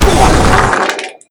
pl_impact_airblast4.wav